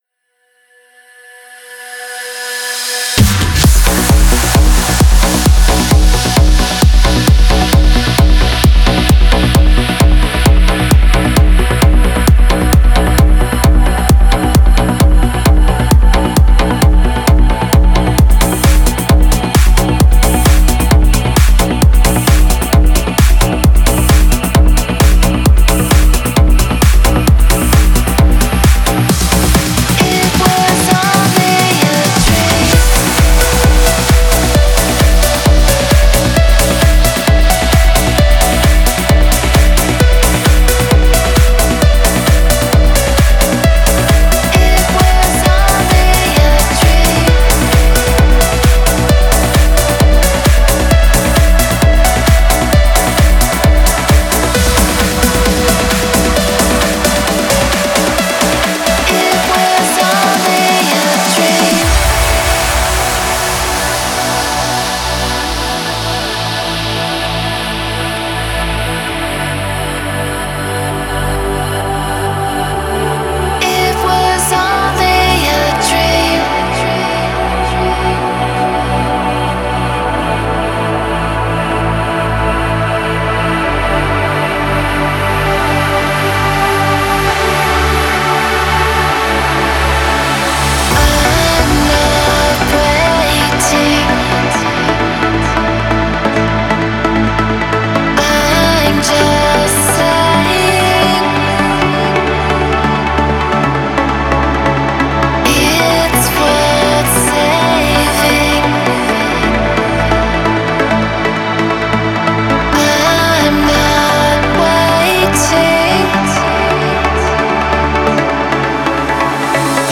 Стиль: Trance / Progressive Trance / Vocal Trance